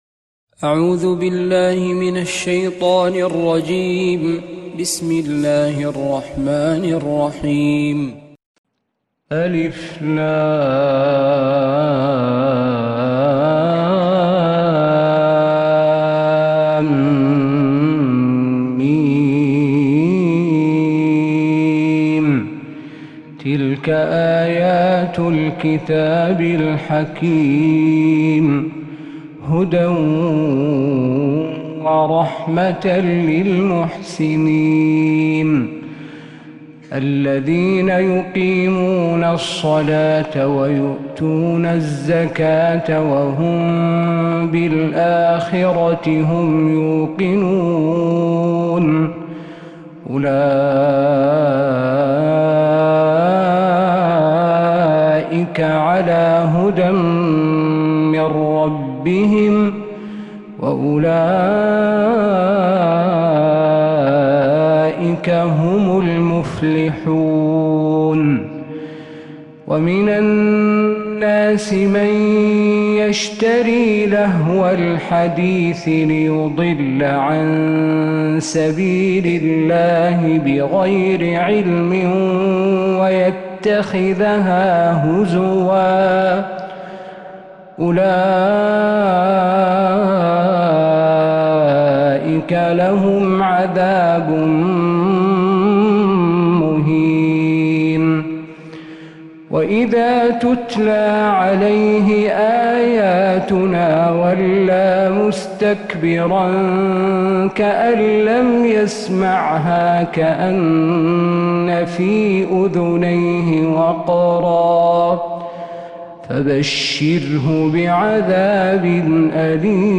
من الحرم النبوي 🕌